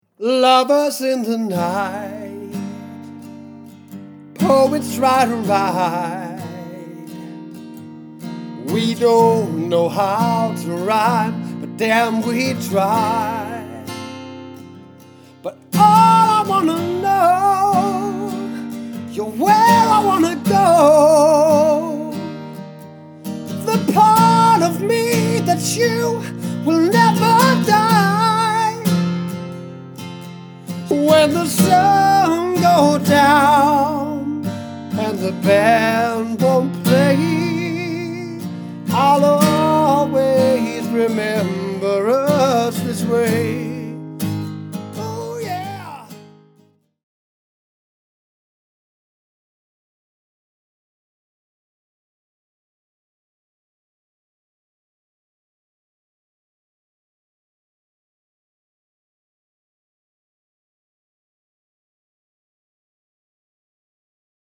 Jeg spiller guitar og synger, og det er det bedste jeg ved.